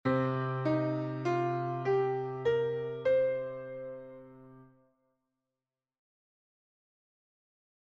Blues Piano
Considérons comme réservoir de notes initial la gamme pentatonique mineure en Do:
gamme-penta-mineure.mp3